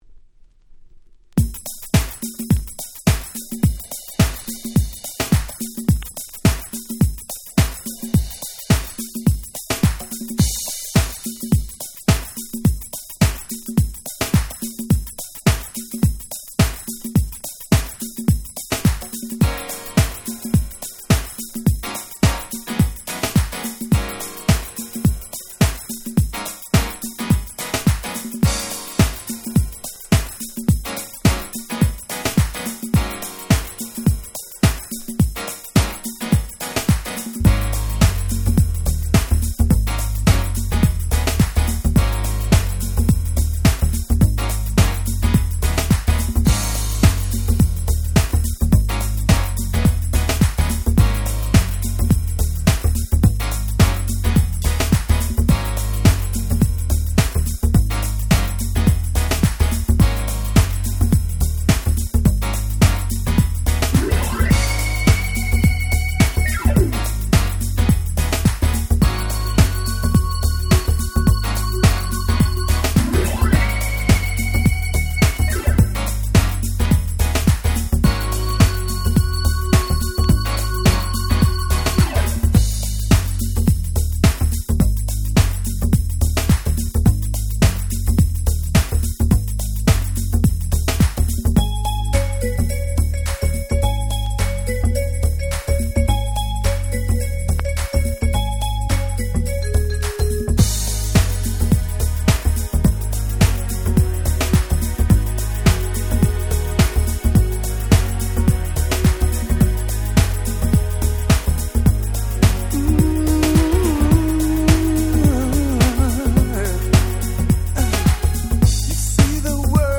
SweetSoulfulな男性Vocal物。
爽快感がハンパないです！
間奏のSaxパートもバッチリ！！